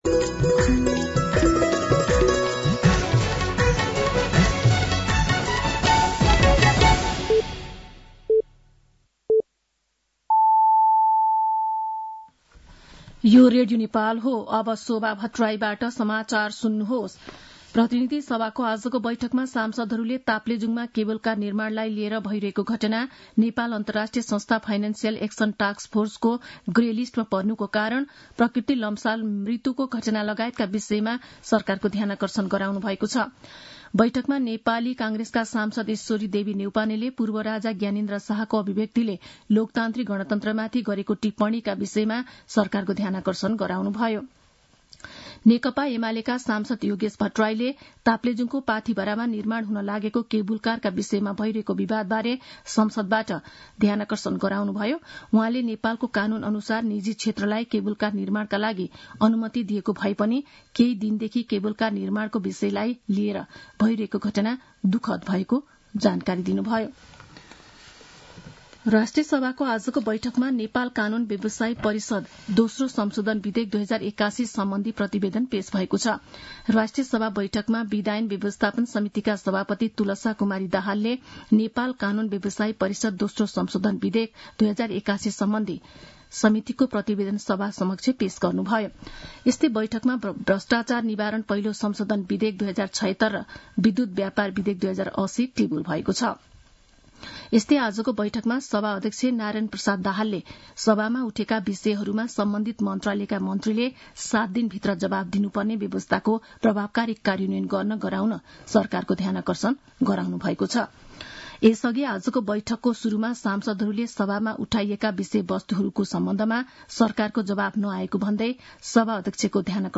साँझ ५ बजेको नेपाली समाचार : १२ फागुन , २०८१
5-pm-news-11-11.mp3